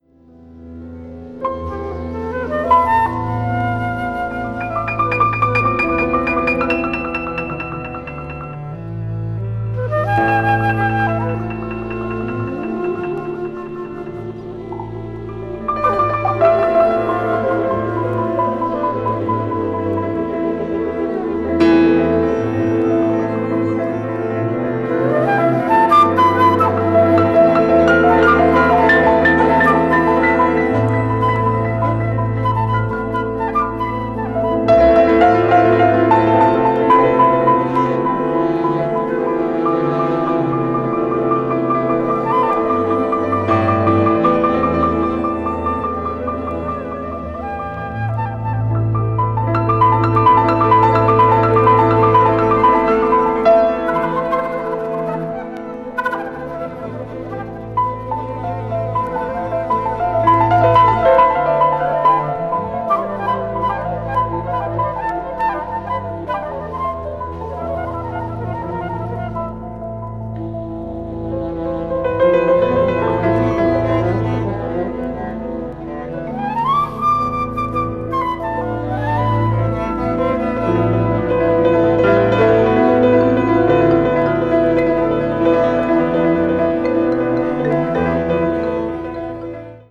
jazz pianist
recorded in Warsaw in 1976